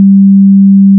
(5 Points) Use the sampling rate and number of samples in one cycle of hw6-1.wav to determine the frequency in the tone in this sound file.